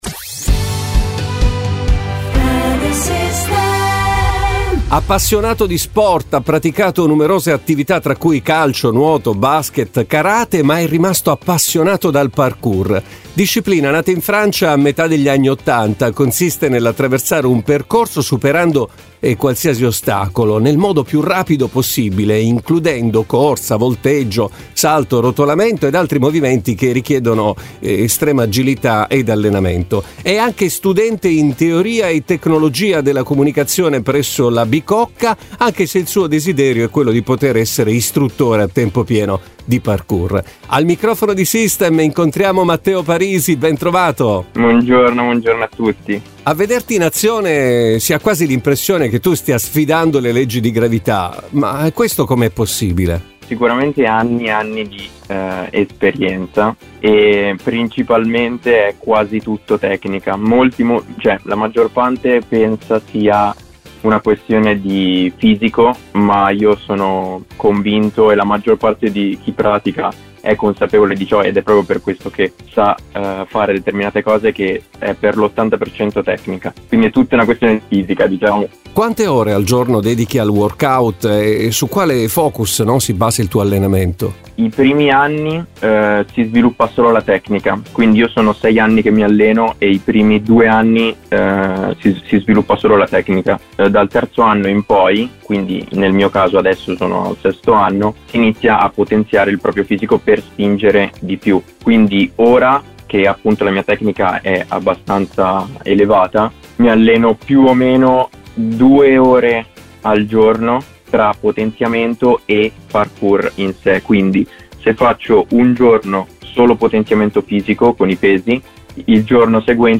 Tutto questo, e molto altro, nella consueta intervista del sabato